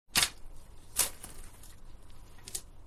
Shovel4.wav